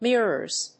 /ˈmɪrɝz(米国英語), ˈmɪrɜ:z(英国英語)/